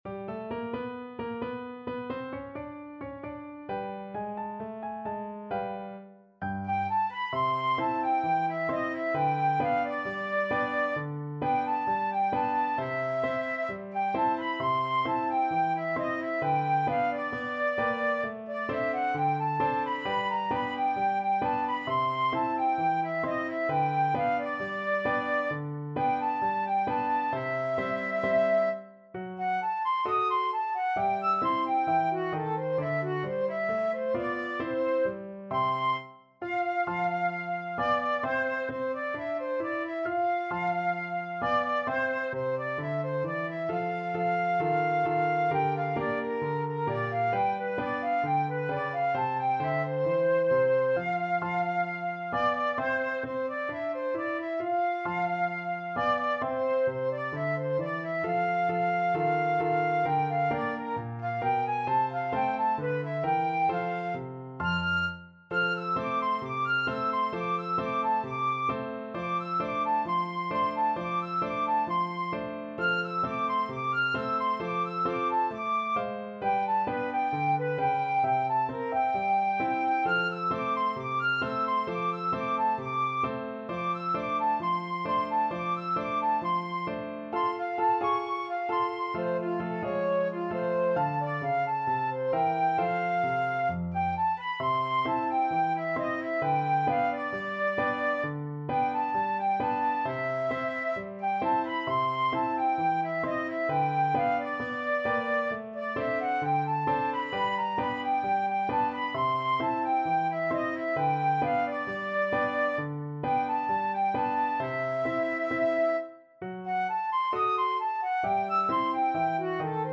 Flute 1Flute 2Piano
Allegro =132 (View more music marked Allegro)
Jazz (View more Jazz Flute Duet Music)